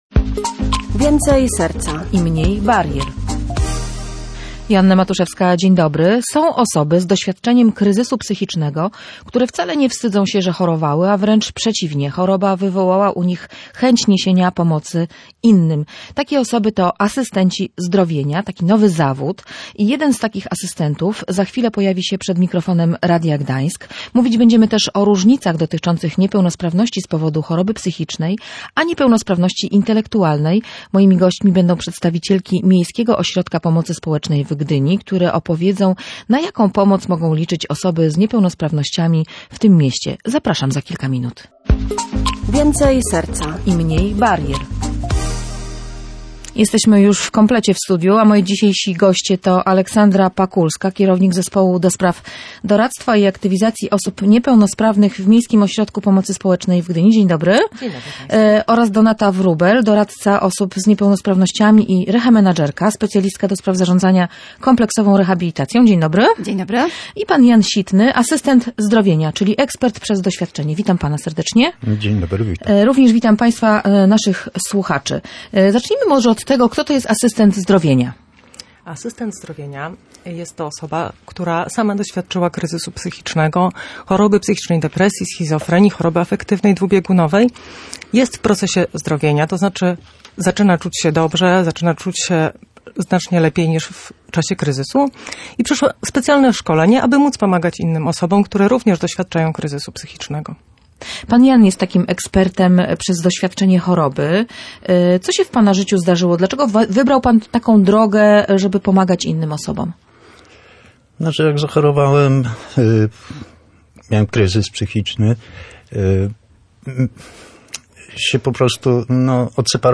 W programie udział wzięły też przedstawicielki Miejskiego Ośrodka Pomocy Społecznej w Gdyni.